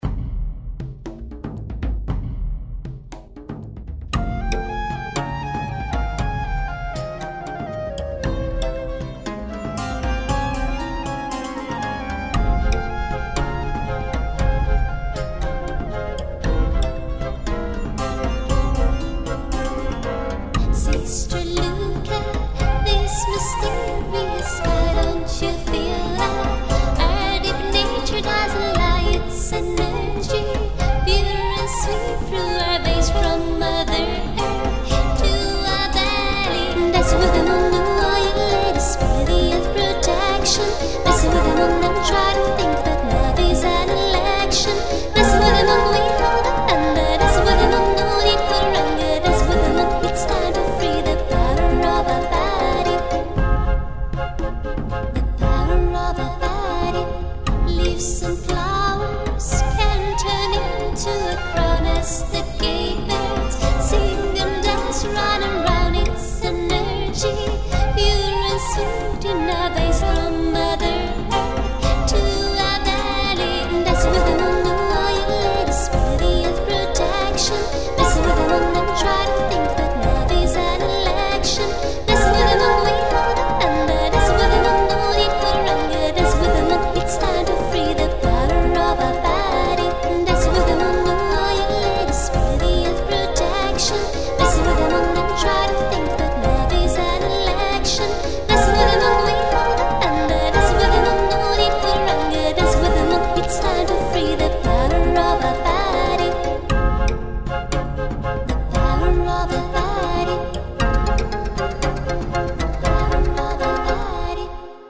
MÚSICAS MEDIOEVALI Y CELTA